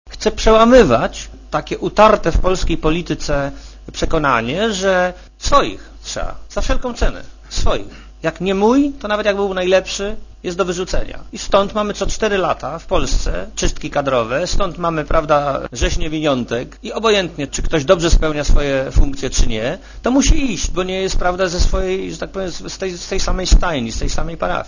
Premier Marek Belka oświadczył, że chce obsadzać stanowiska państwowe ludźmi kompetentnymi niezależnie od ich przynależności partyjnej. Premier powiedział na konferencji prasowej w Spale, że chce złamać obowiązującą dotąd zasadę, według której partia przejmująca władzę wprowadzała swoich ludzi na wszystkie możliwe stanowiska.
Posłuchaj premiera Belki